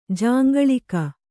♪ jāŋgaḷika